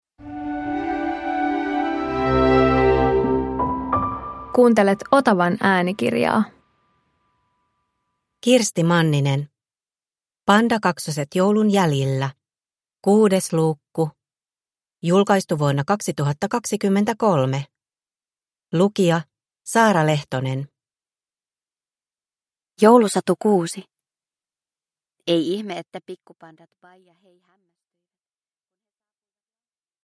Pandakaksoset joulun jäljillä 6 – Ljudbok